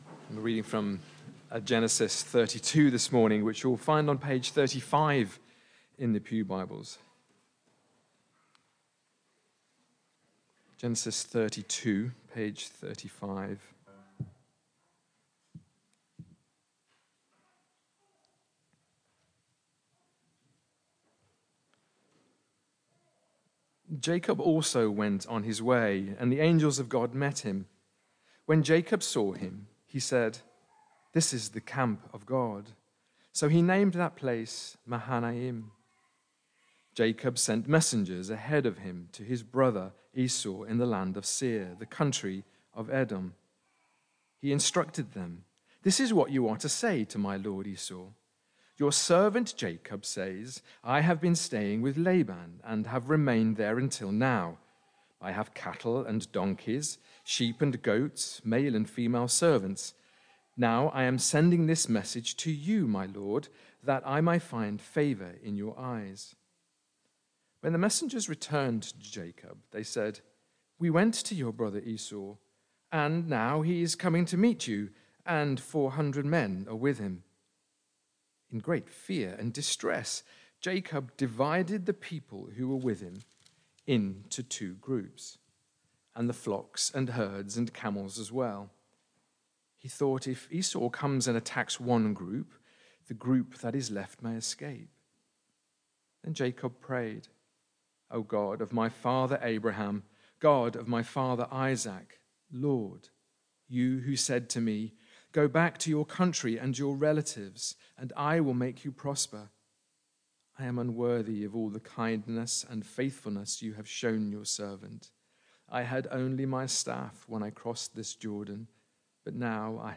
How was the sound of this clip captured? Barkham Morning Service